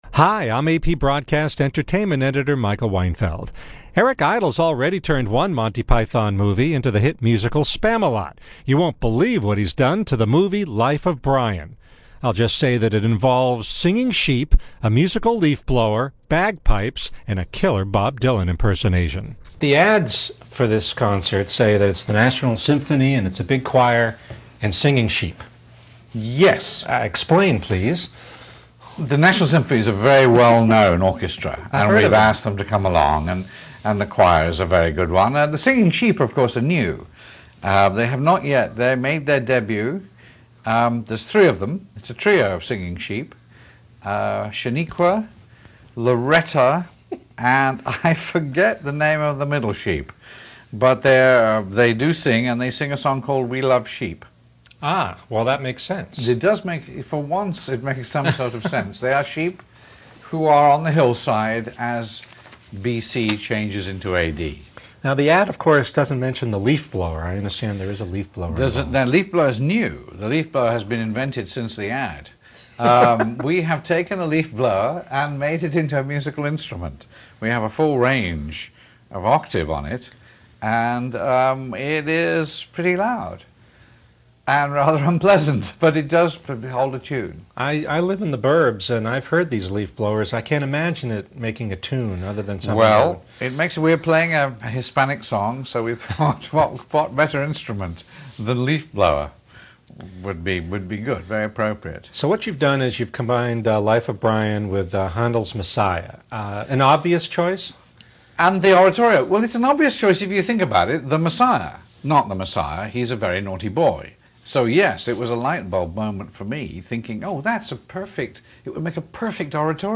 Eric Idle interview.mp3